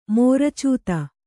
♪ mōra cūta